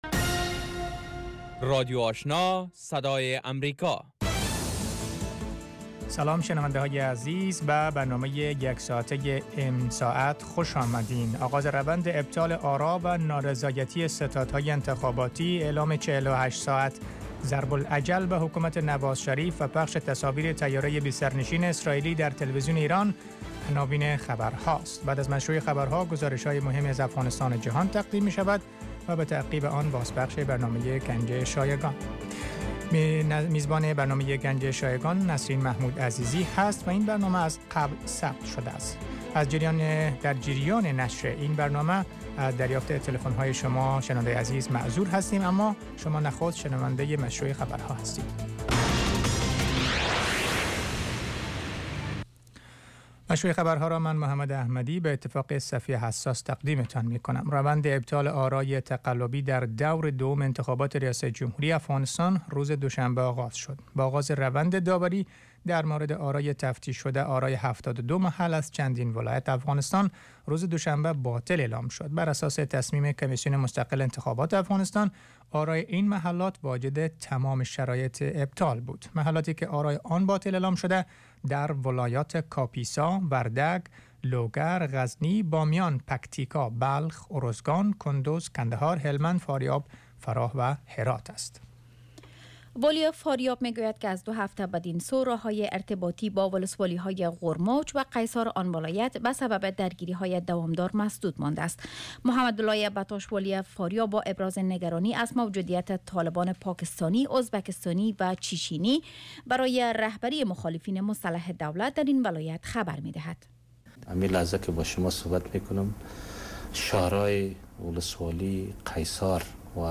سومین برنامه خبری شب